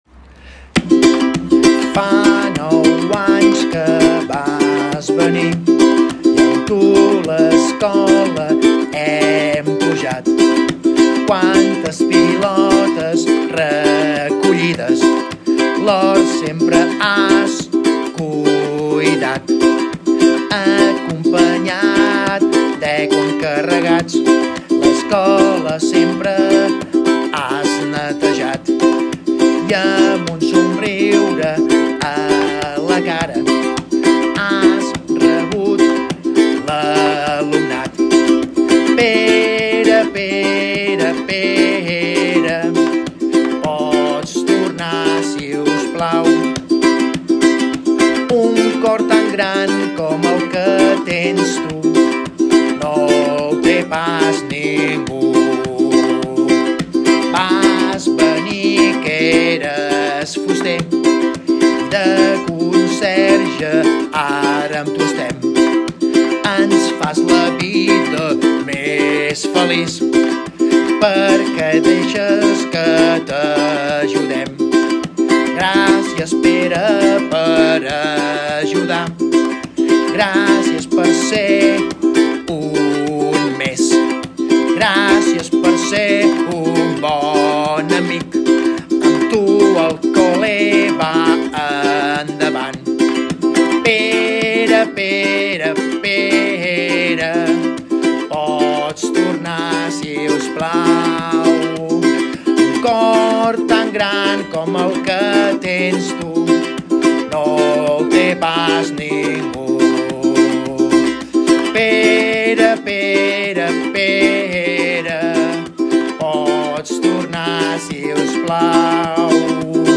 Us deixem la cançó que els infants van preparar amb els nostres Especialistes de Música.